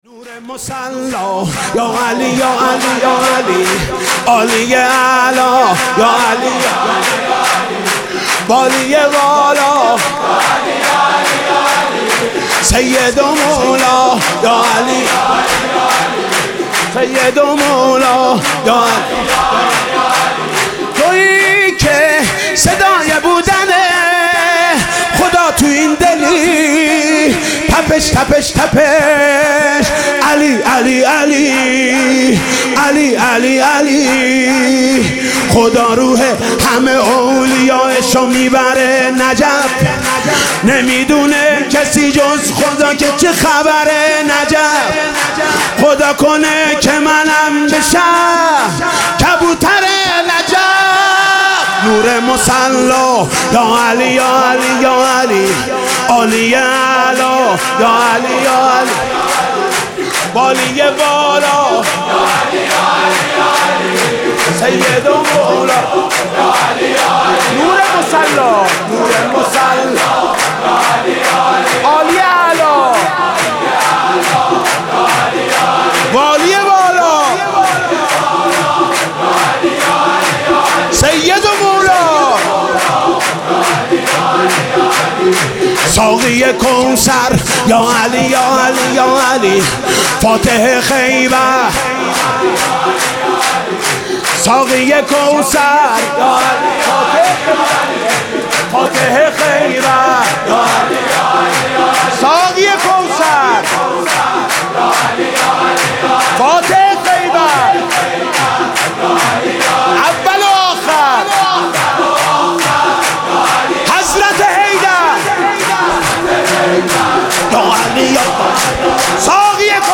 سرود: نور مصلی یاعلی یاعلی یاعلی